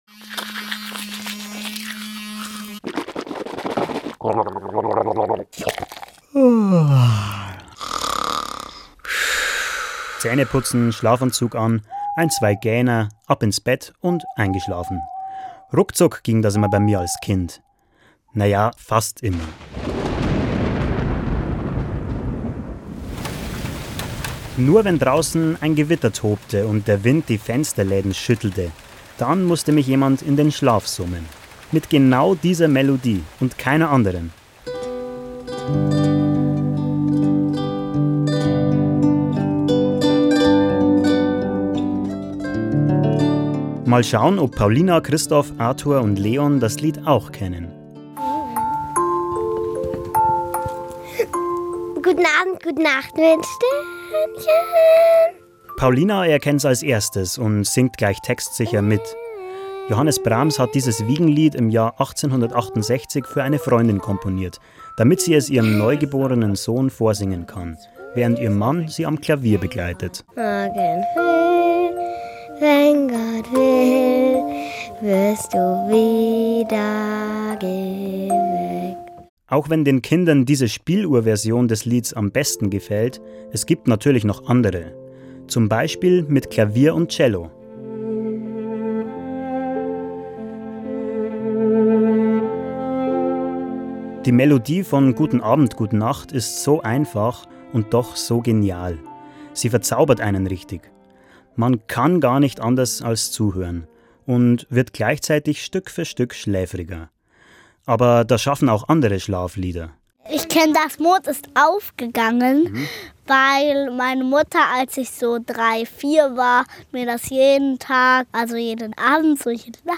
Und wie klingt es, wenn Kinder eine neue Strophe zu "Guten Abend, gute Nacht" dazu dichten?